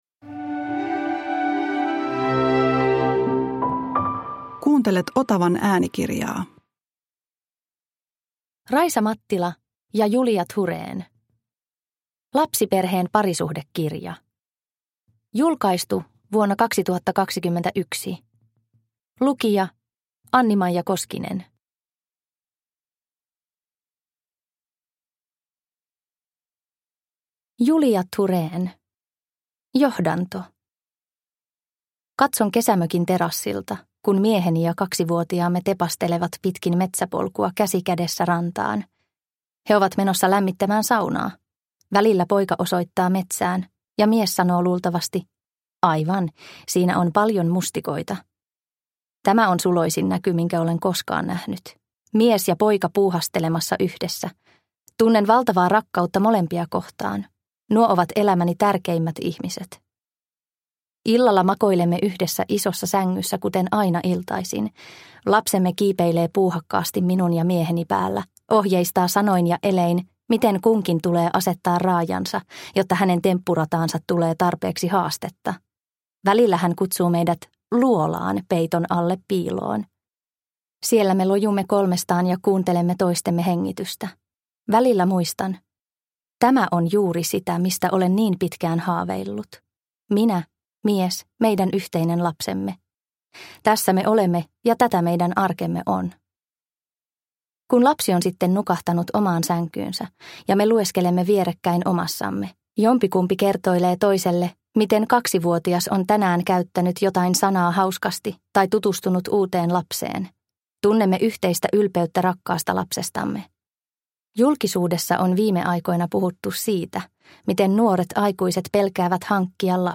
Lapsiperheen parisuhdekirja – Ljudbok – Laddas ner